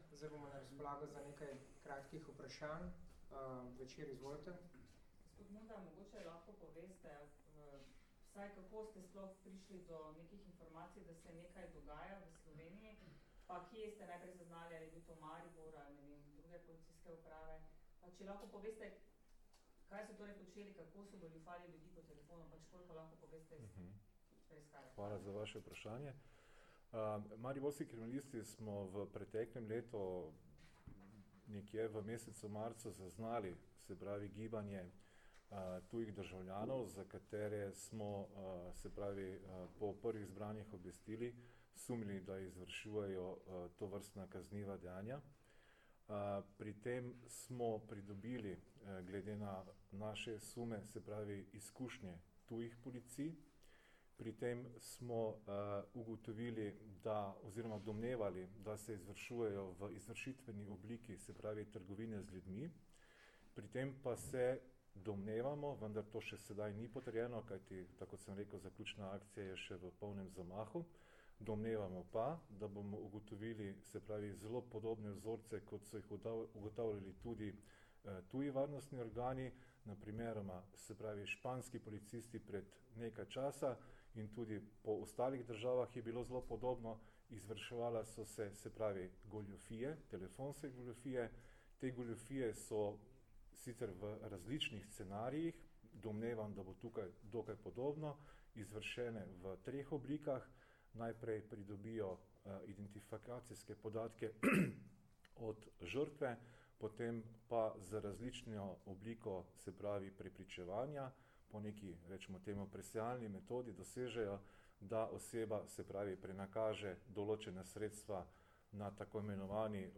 Vprašanja in odgovori z izjave za javnost (mp3)